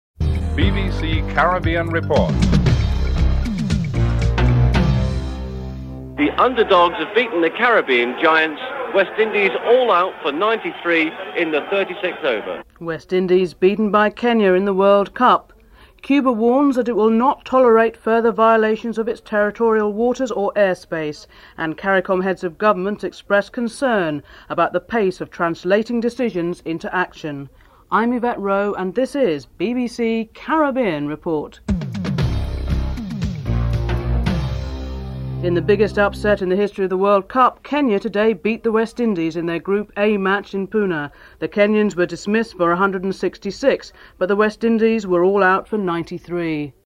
1. Headlines (00:00-00:34)
President Cheddi Jagan is interviewed (10:57-12:07)